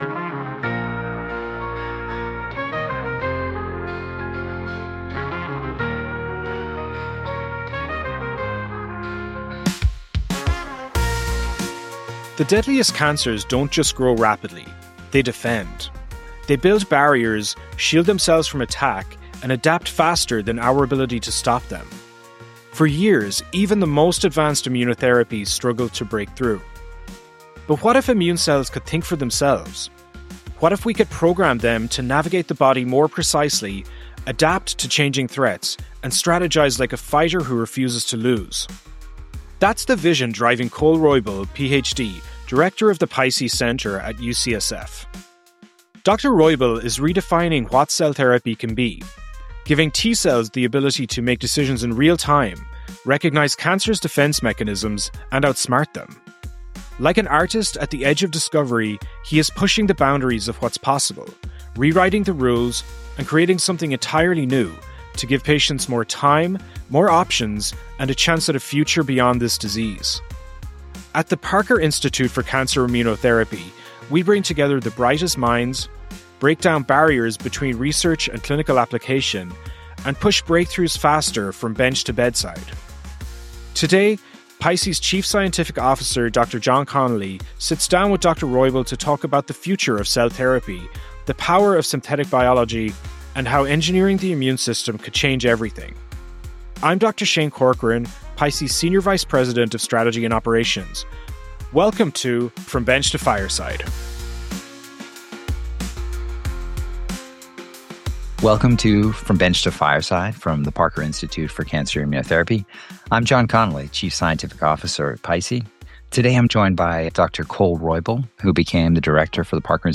From Bench to Fireside™ is a new podcast from the Parker Institute for Cancer Immunotherapy (PICI) that takes you from behind the lab bench to the frontiers of cancer research. Each episode features an interview with members of the PICI Network, pioneers in cancer immunotherapy and leaders at world-renowned cancer research institutions.